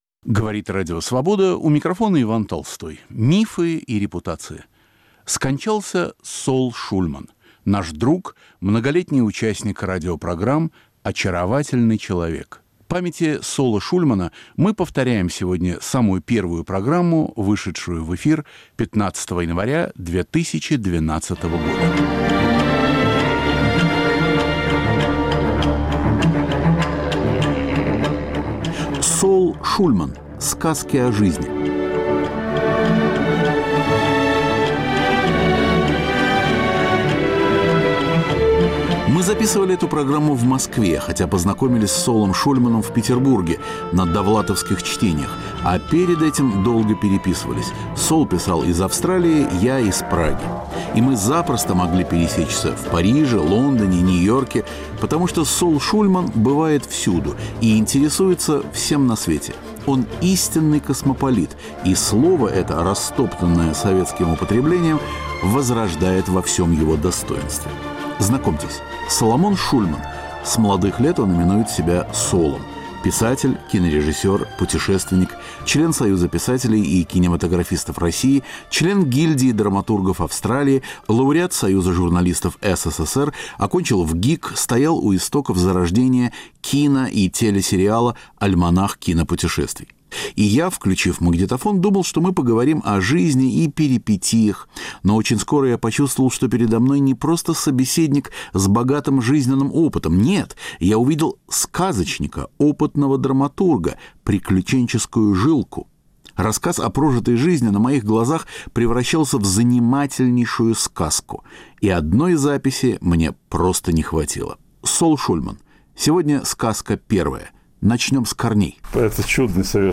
Вспоминая друга, мы повторяем сегодня первую с ним беседу, вышедшую в эфир 15 января 2012 года.